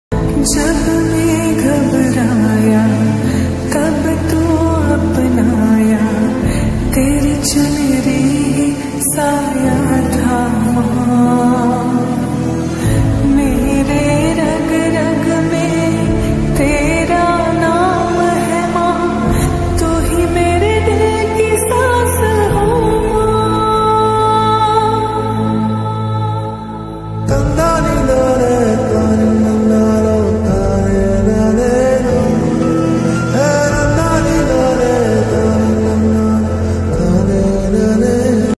love song ringtone
Hindi ringtone music ringtone